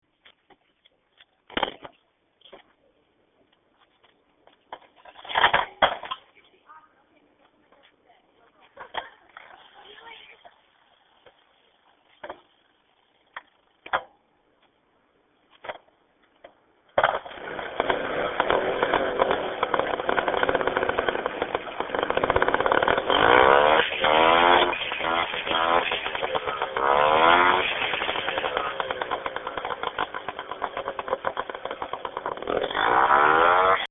Sounds of Moped Starting, or Leaving the UW
moped-sounds.mp3